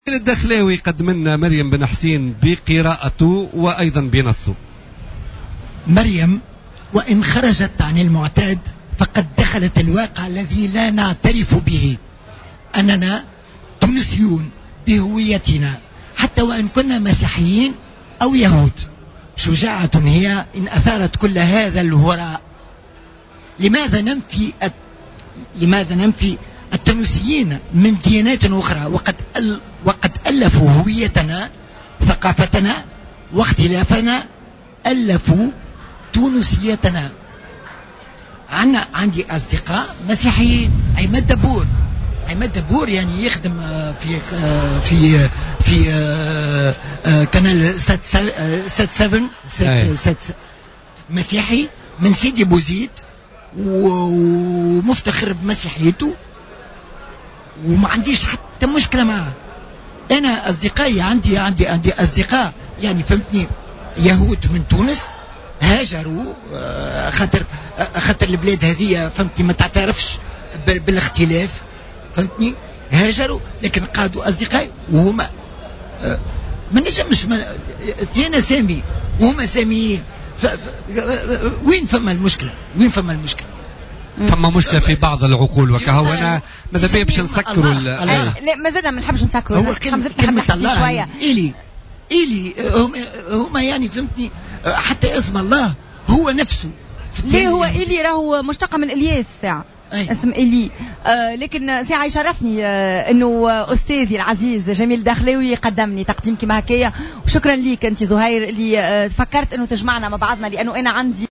على هامش المهرجان العربي للإذاعة والتلفزيون المنعقد حاليا في الحمامات